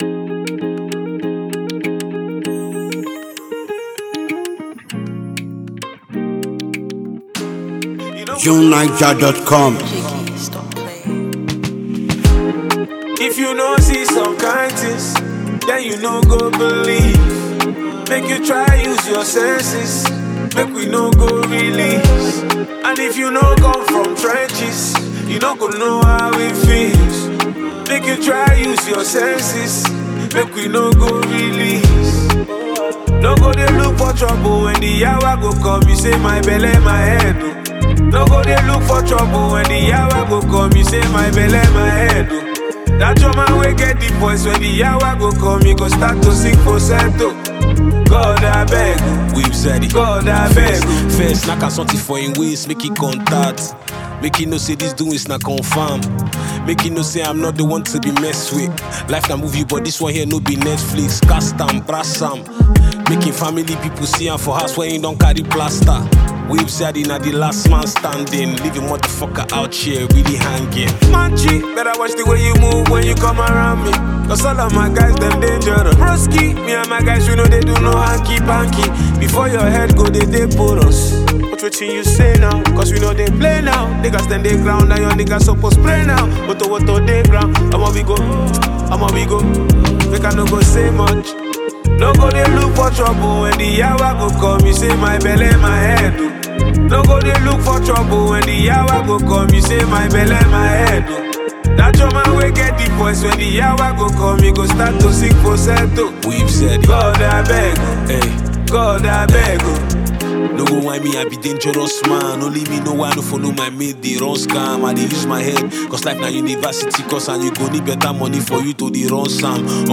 a highly regarded and exceptionally talented Nigerian singer
buzzing and masterfully composed smash tune